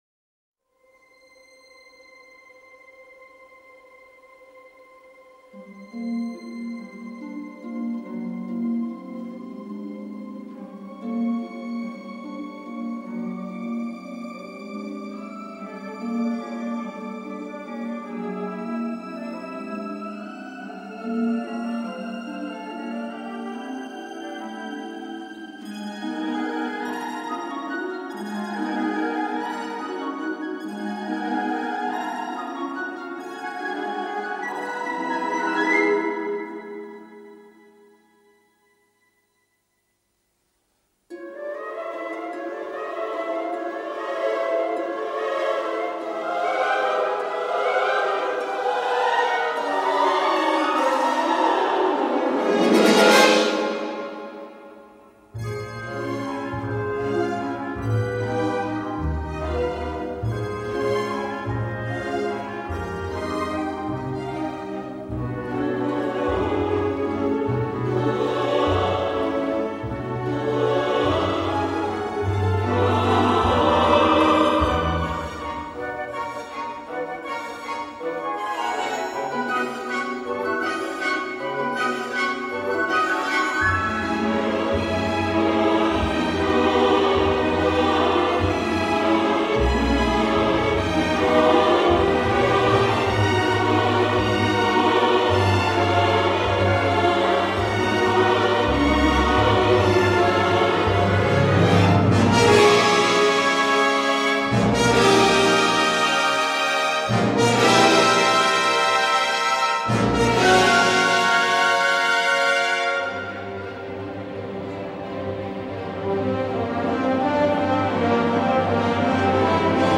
superbe score riche, chatoyant et ambitieux